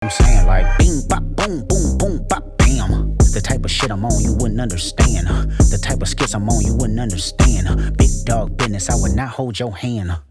Tip The Creator: rap